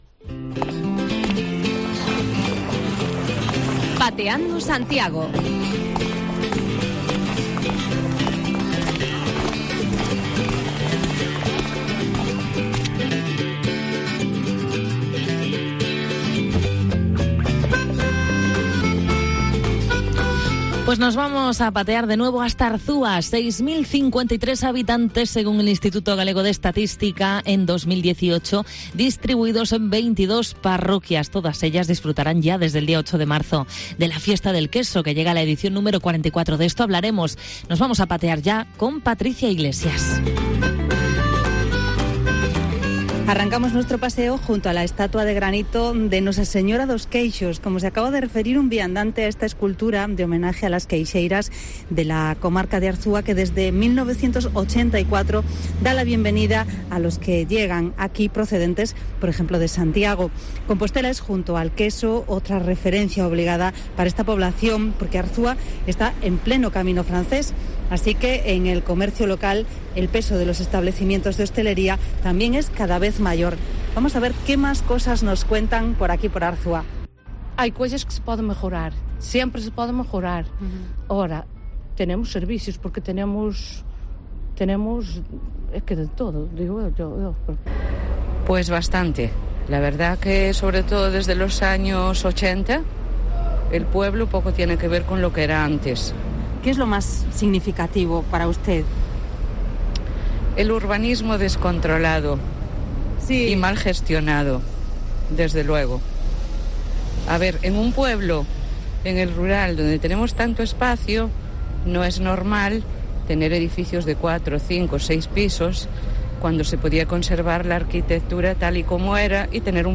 Pateamos por esta localidad, a poco más de 40 kilómetros de Santiago, metida de lleno en los preparativos de una de sus celebraciones más destacadas la Festa do Queixo, que este año llega a su edición número 44.
Carpa para la Festa do Queixo de este fin de semana En nuestro paseo nos hemos encontrado muchos residentes en el núcleo de Arzúa pero también vecinos de alguna de las 22 parroquias que tiene este término municipal y que acuden periódicamente a la localidad para trabajar o hacer compras.